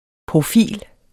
Udtale [ pʁoˈfiˀl ]